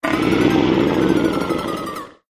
furious_03.ogg